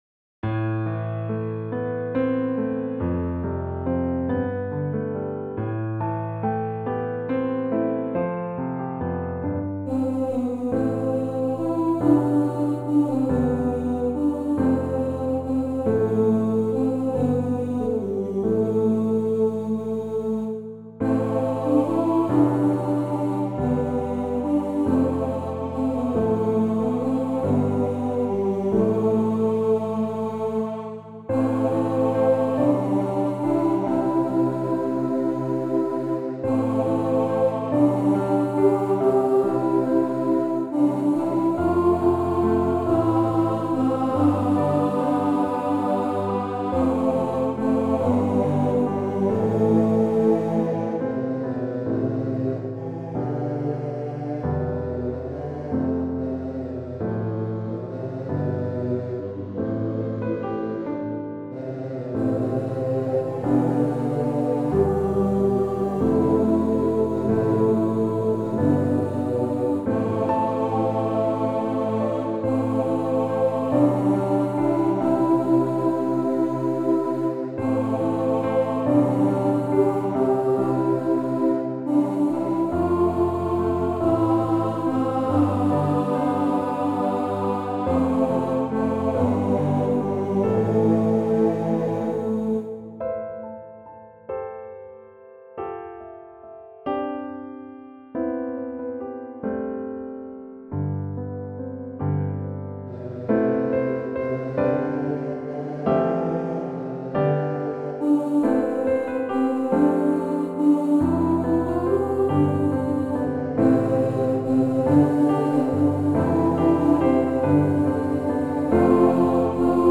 Arranged in the key of A minor. Accompanied by piano.
Voicing/Instrumentation: SATB